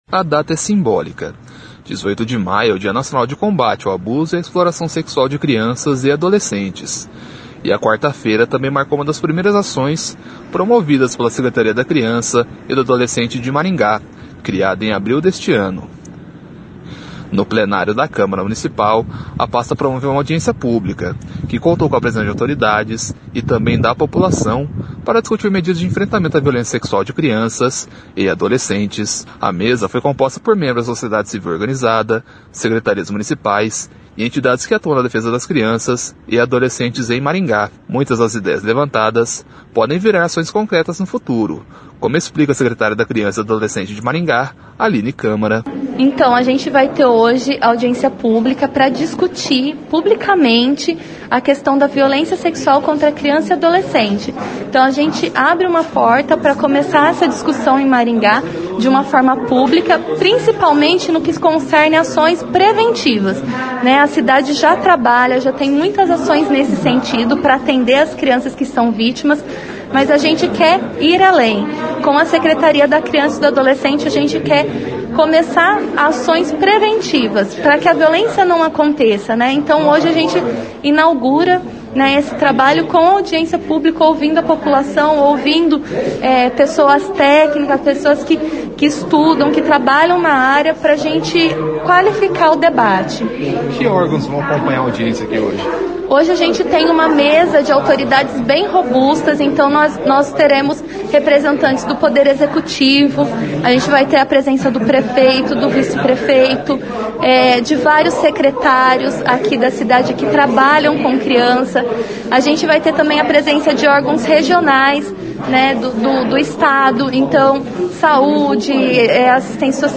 O encontro, promovido pela Secretaria da Criança e do Adolescente de Maringá, foi realizado na tarde desta quarta-feira (18), na Câmara de Maringá.
Muitas das ideias levantadas podem virar ações concretas no futuro, como explica a secretária da Criança e do Adolescente de Maringá, Aline Câmara. [ouça no áudio acima]
Segundo o prefeito de Maringá, Ulisses Maia, a discussão é fundamental para saber o que a sociedade pensa sobre o tema. [ouça no áudio acima]